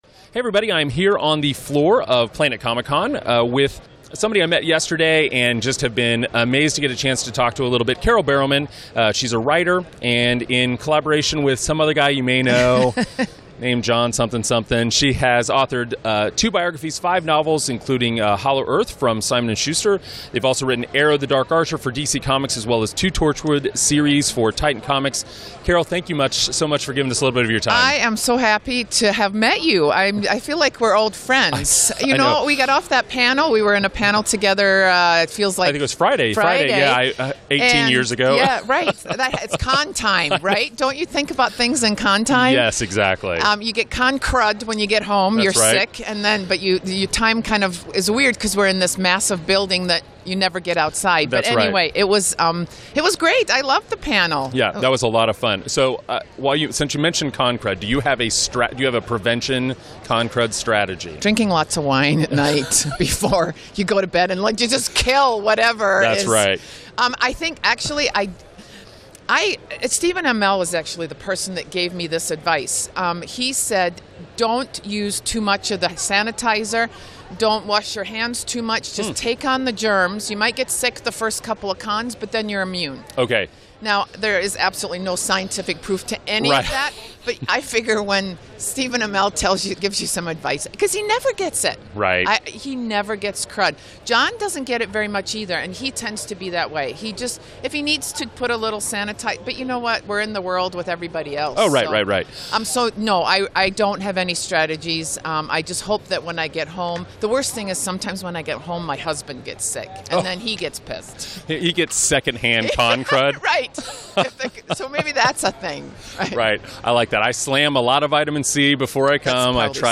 WCPE Interview